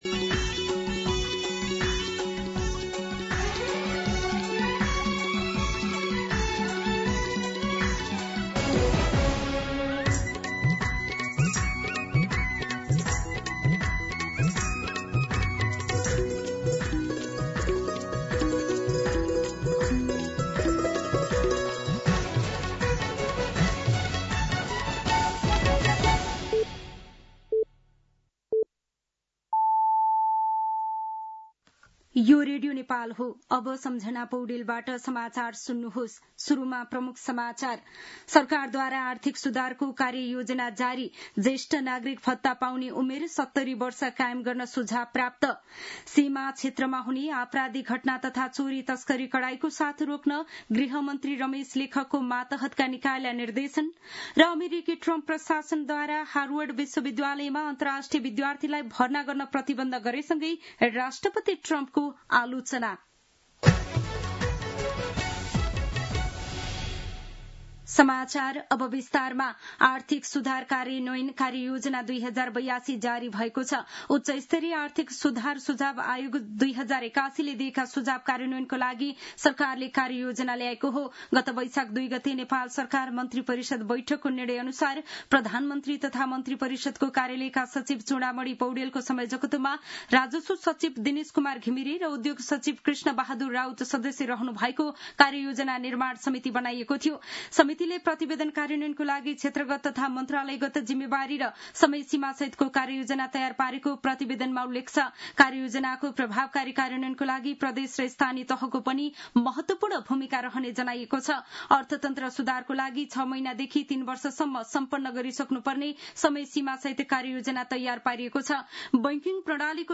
दिउँसो ३ बजेको नेपाली समाचार : ९ जेठ , २०८२